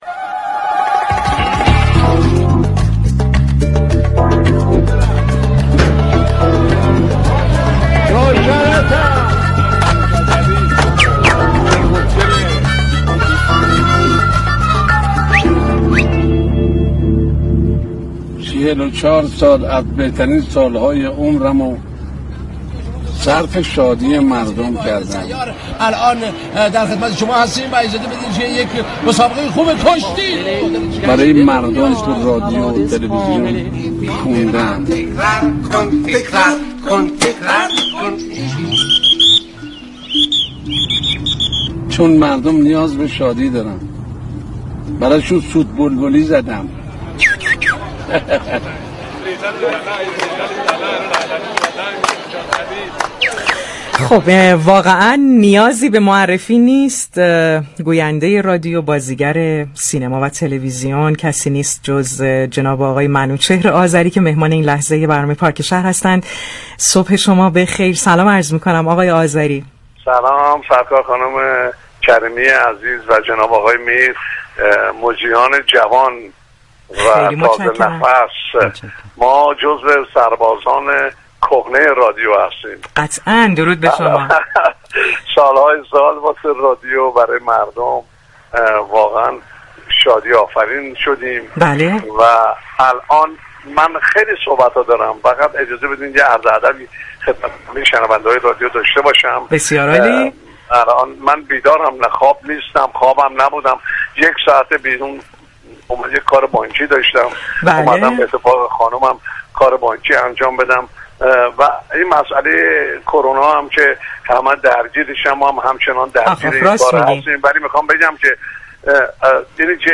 او كه با سوت های معروفش مخاطبان رادیو را به سالهای دور و برنامه های شاد رادیو برد گفت: خدا همه عزیزانی كه مردم را شاد می‌كردند بیامرزد بزرگانی مانند منوچهر نوذری و صدرالدین شجره. او كه به همراه همسرش برای انجام كار بانكی بیرون از خانه بود به مخاطبان پارك شهر گفت: 50 سال است با همسرم زندگی می‌كنم و او همه عشق من و زندگی من است.